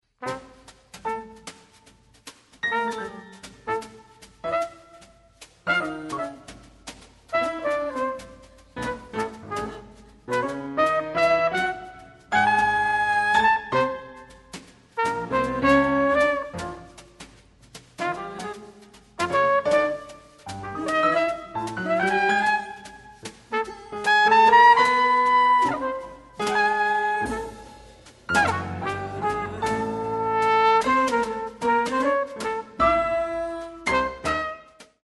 Piano
Trumpet & Flugelhorn
Percussion